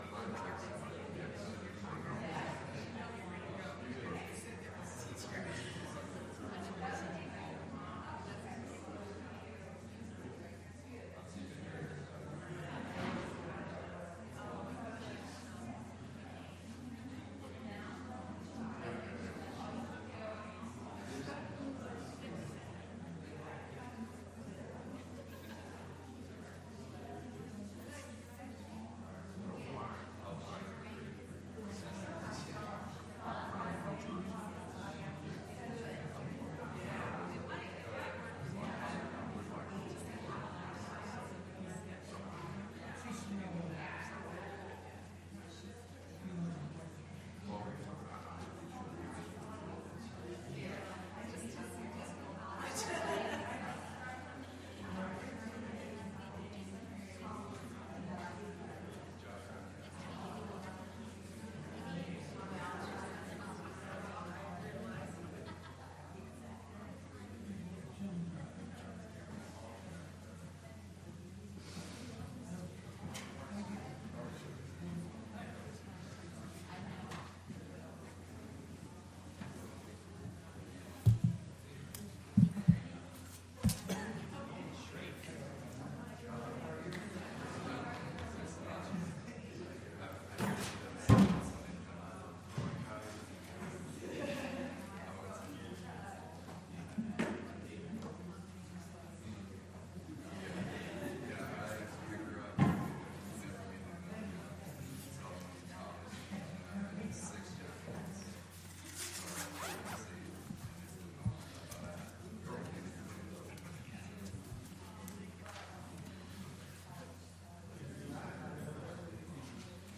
Bible Study: 1Cor5-6